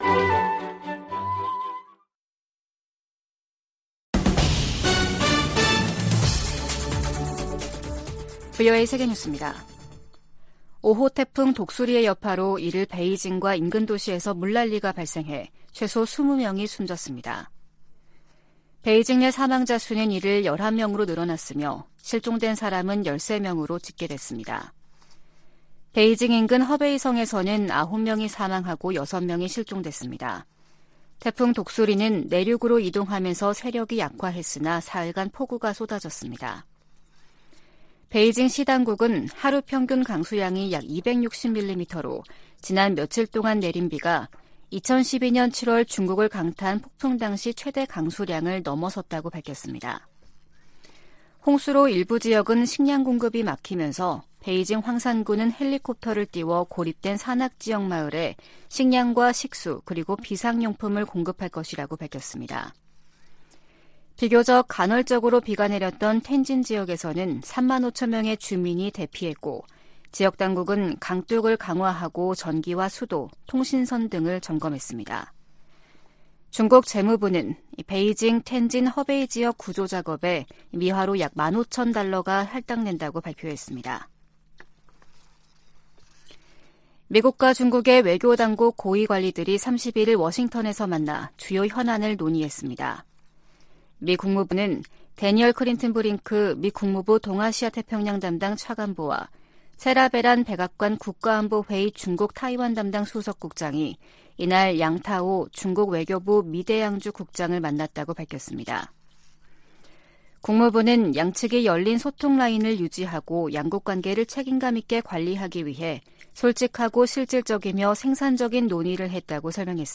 VOA 한국어 아침 뉴스 프로그램 '워싱턴 뉴스 광장' 2023년 8월 2일 방송입니다. 린다 토머스-그린필드 유엔 주재 미국대사가 북한의 식량 불안정 문제는 정권이 자초한 것이라고 지적했습니다. 제11차 핵확산금지조약(NPT) 평가회의 첫 준비 회의에서 주요 당사국들은 북한이 비확산 체제에 도전하고 있다고 비판했습니다. 북한과 러시아 간 무기 거래 가능성이 제기되는 데 대해 미국 국무부는 추가 제재에 주저하지 않을 것이라고 강조했습니다.